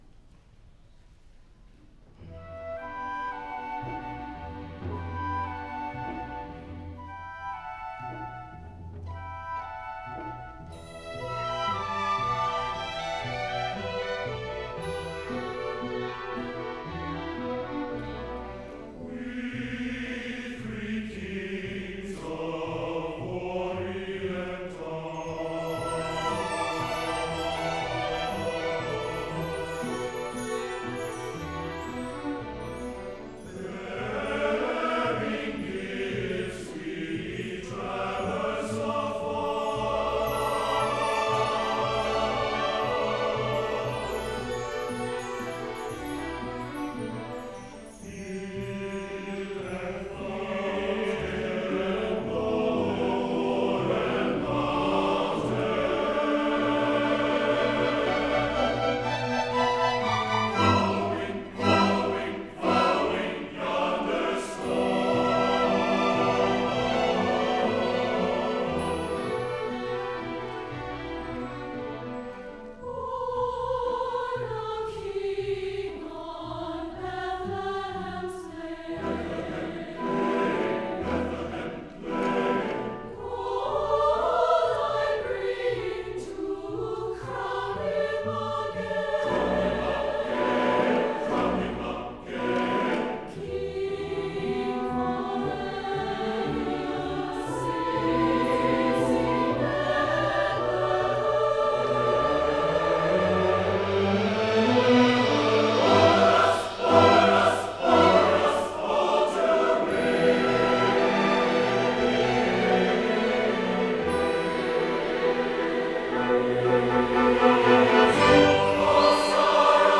for SATB Chorus and Orchestra (2009)
orchestra version